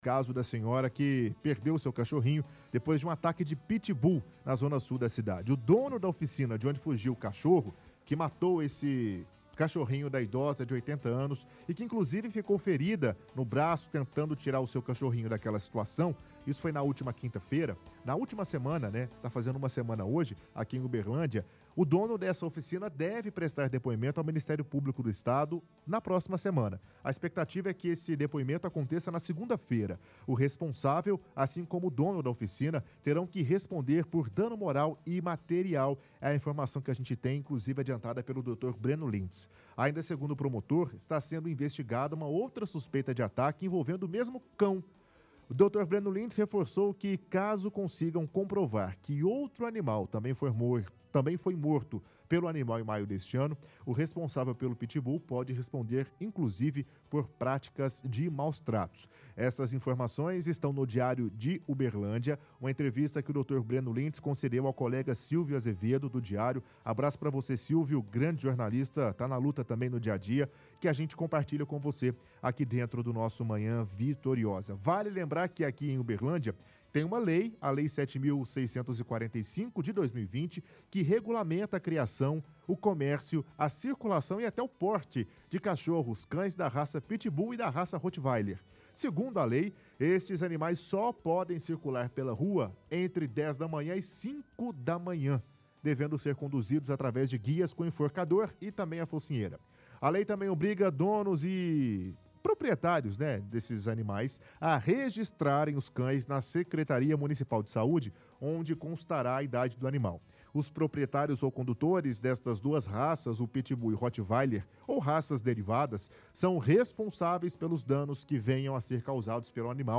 – Leitura do jornal Diário de Uberlândia.